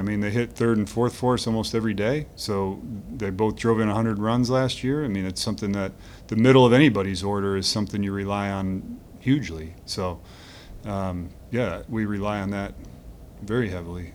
Royals mgr. Matt Quatraro on not getting production from No. 3 and 4 hitters Vinnie Pasquantino and Salvador Perez.